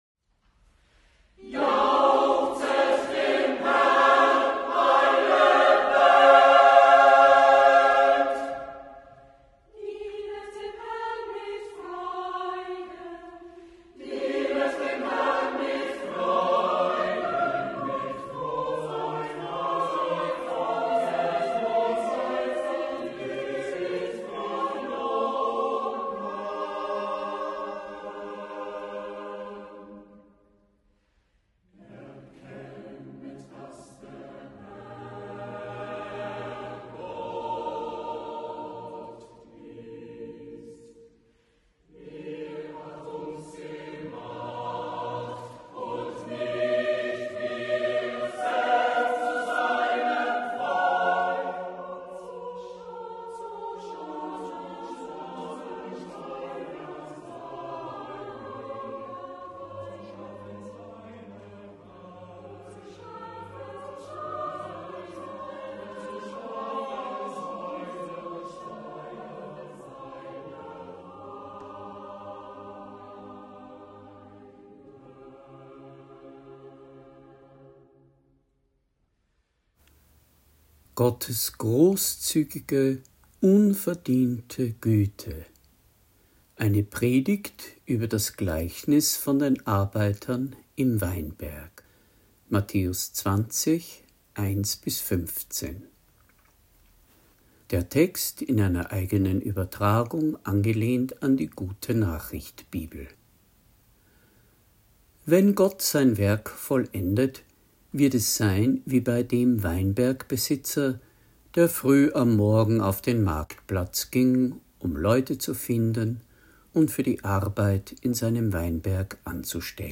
Predigt | NT01 Matthäus 20,1-15 Die Arbeiter im Weinberg